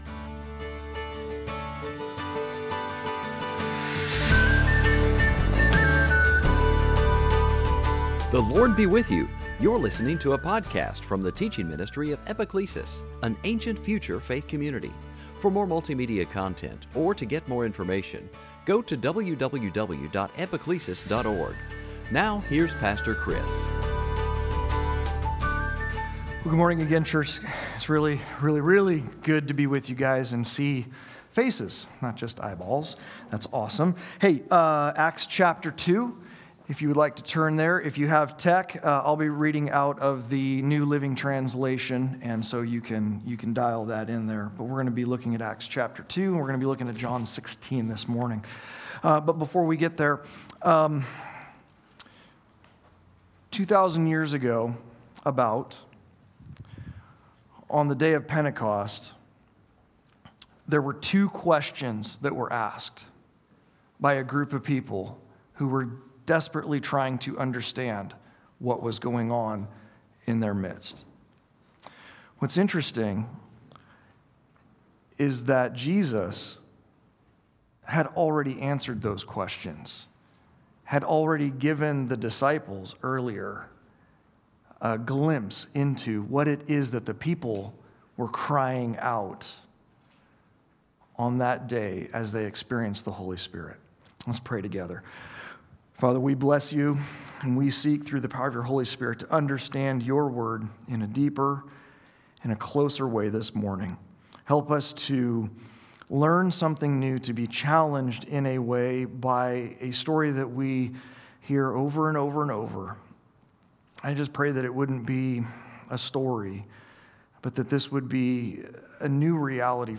Series: Sunday Teaching | On this Pentecost Sunday we investigate when and how Jesus had already answered the two questions of the crowd in Jerusalem. What was it that Jesus wanted his disciples to grab hold of about the work of the Holy Spirit?